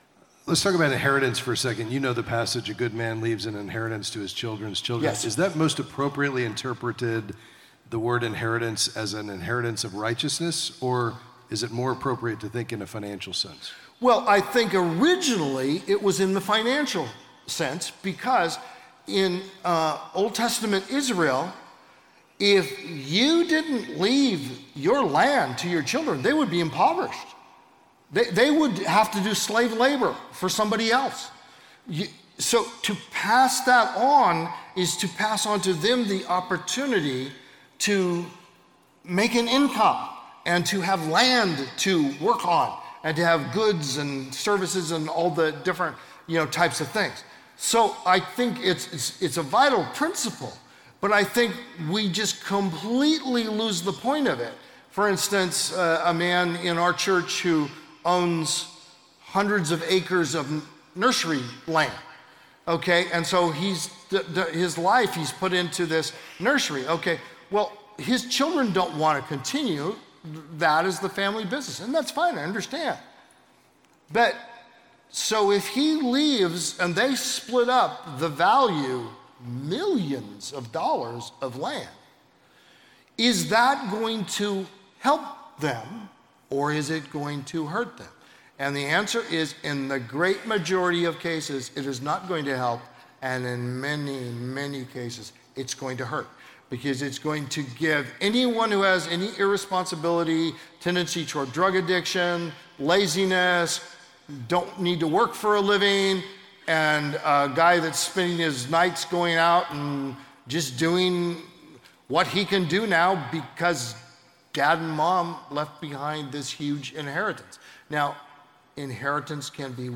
In this clip from a Q&A at the Kingdom Advisors Conference